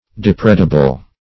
Search Result for " depredable" : The Collaborative International Dictionary of English v.0.48: Depredable \Dep"re*da*ble\, a. Liable to depredation.